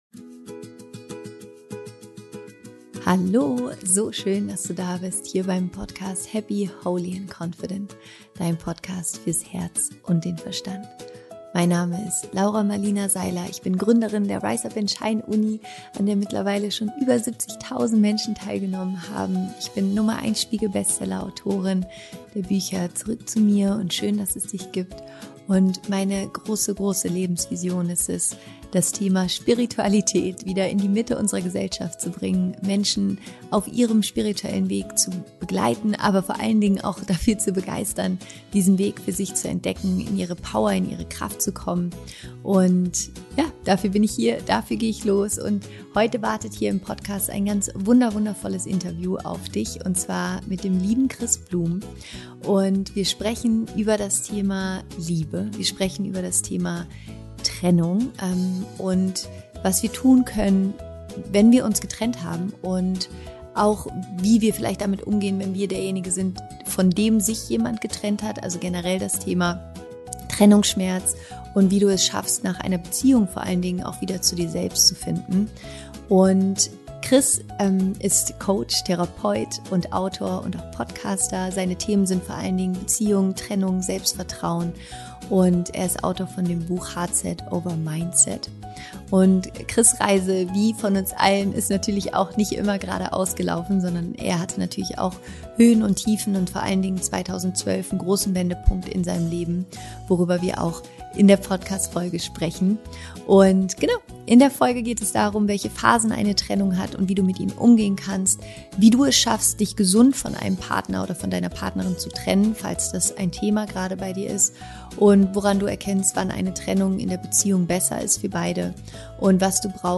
Im heutigen Interview sprechen wir vor allem über das Thema Trennung als spirituelle Lernerfahrung. Es geht auch darum, was du tun kannst, wenn du merkst, dass es mit deinem*r Partner*in einfach nicht mehr funktioniert.